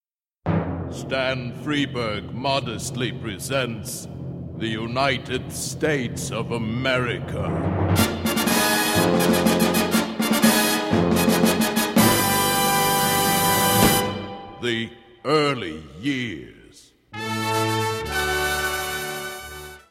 The great Paul Frees had provided the super-authoritative voice of the Narrator.
USAPaul Frees.mp3